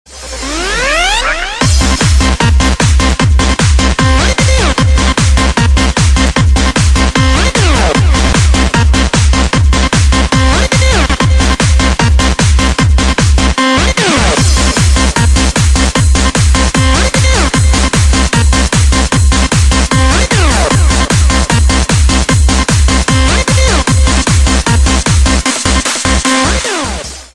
DJ铃声